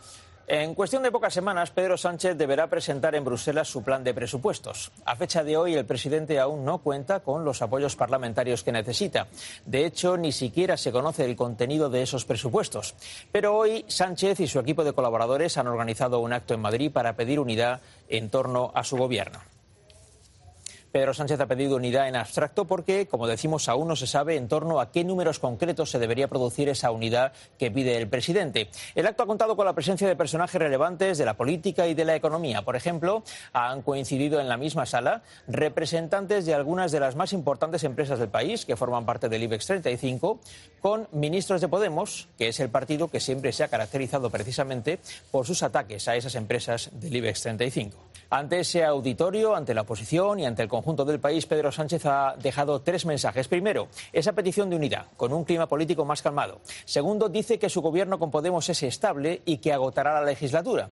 El presentador Vicente Vallés ha cargado contra la propaganda dirigida por Iván Redondo y el doble discurso de Podemos con el Ibex